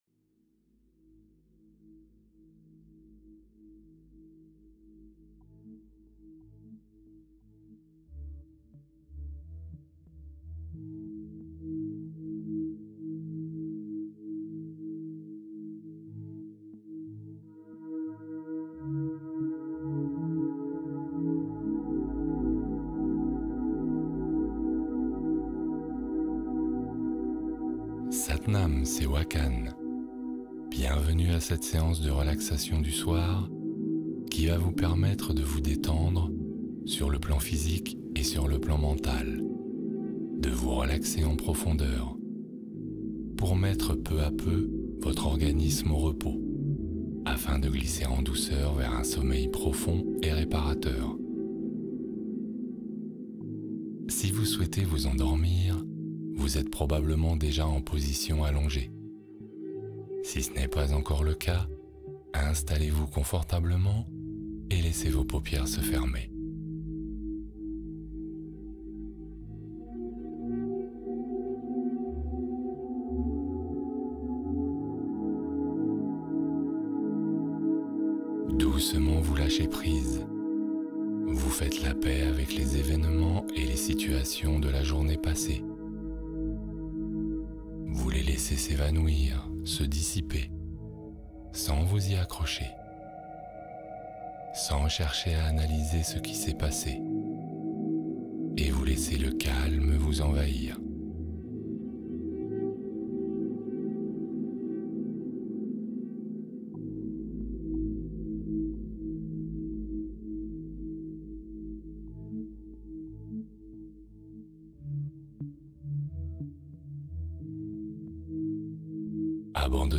Sommeil profond : s’endormir sereinement avec une guidance apaisante